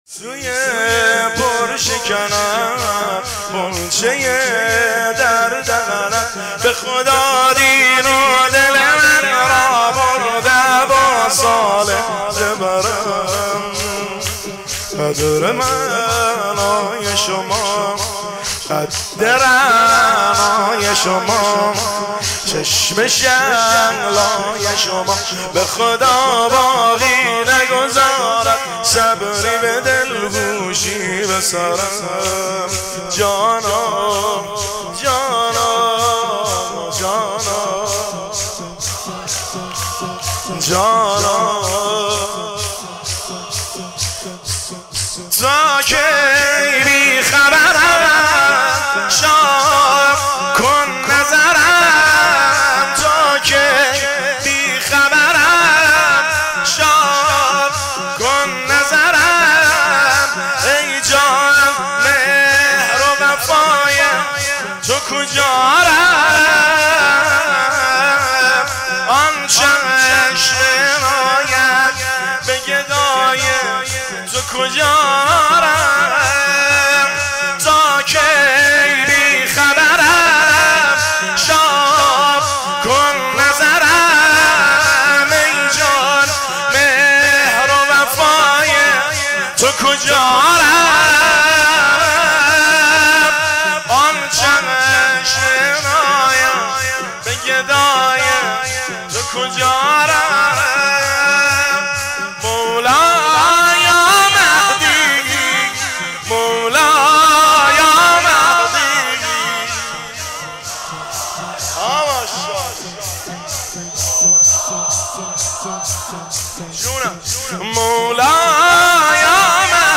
دانلود با کیفیت LIVE